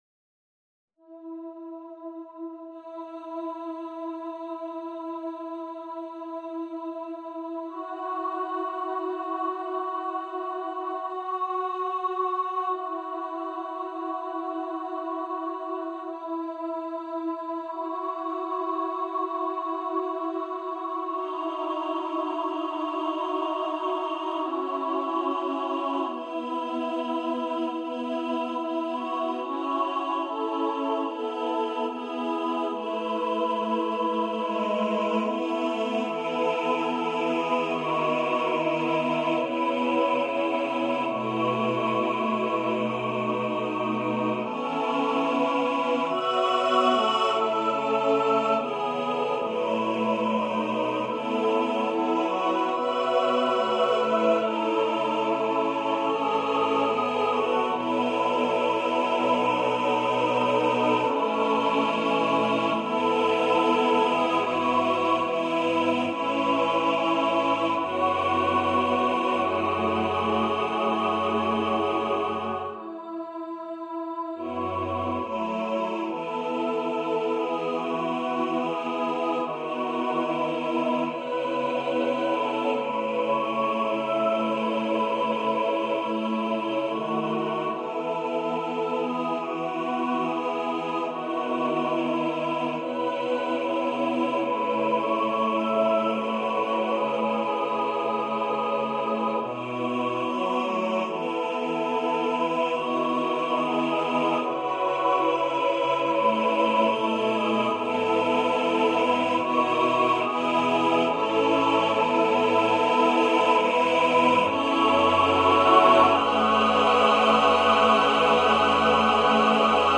for mixed voice choir
Choir - Mixed voices (SATB)